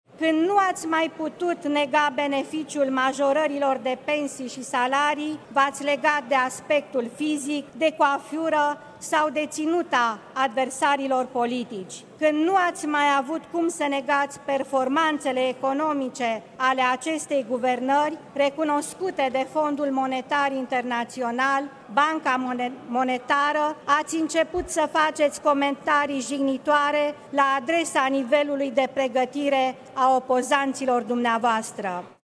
În timpul discursului susținut de premier,  parlamentarii Opoziției i-au cerut demisia.